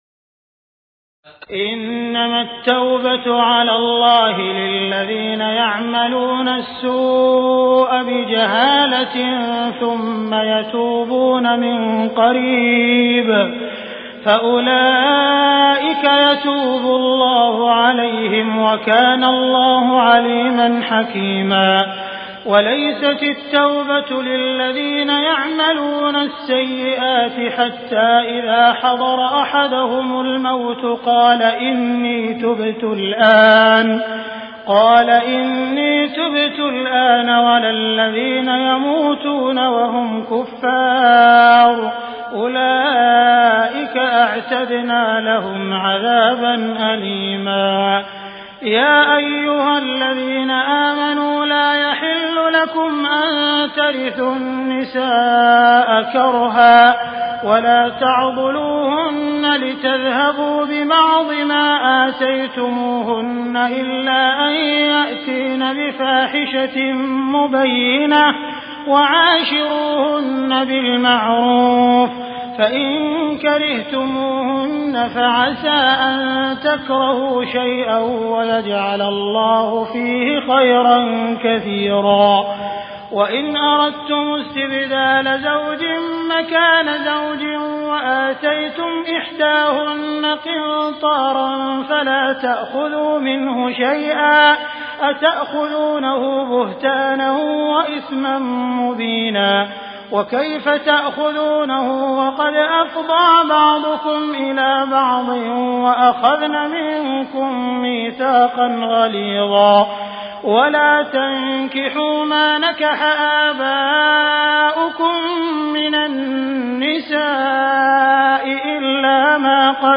تراويح الليلة الخامسة رمضان 1418هـ من سورة النساء (17-87) Taraweeh 5 st night Ramadan 1418H from Surah An-Nisaa > تراويح الحرم المكي عام 1418 🕋 > التراويح - تلاوات الحرمين